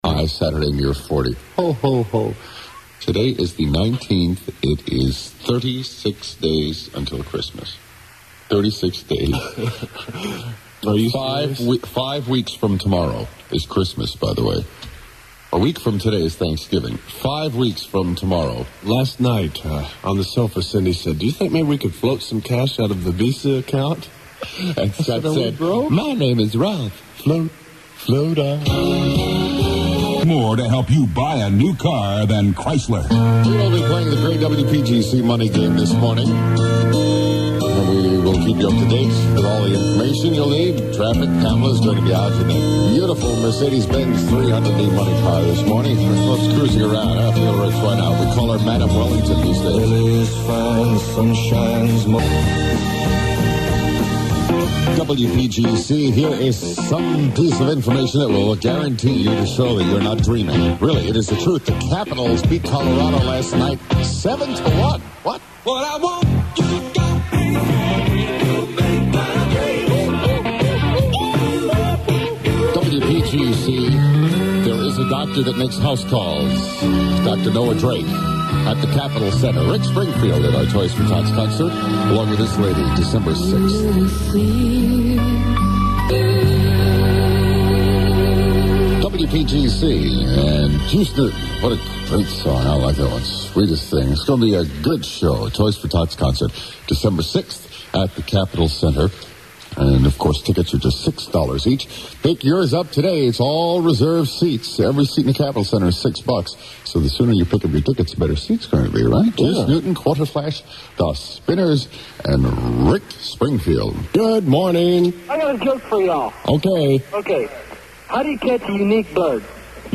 Comedy phone bits however are intact.